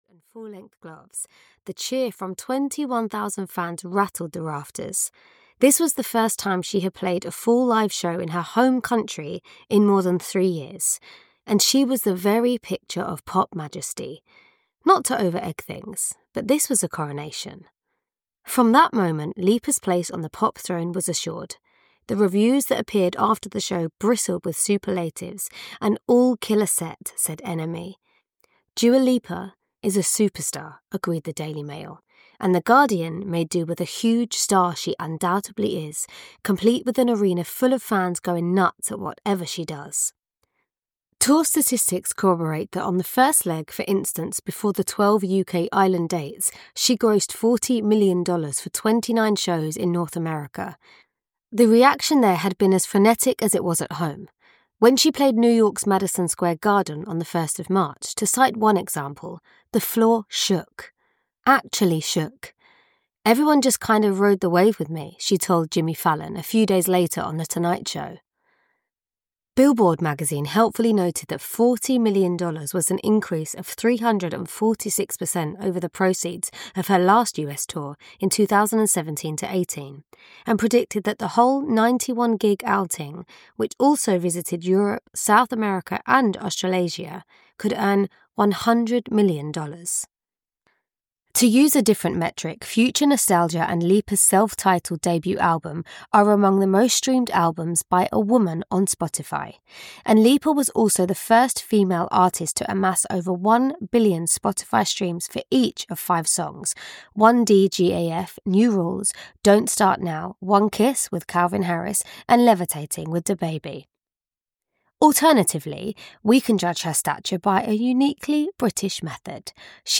Ukázka z knihy
• InterpretLouisa Lytton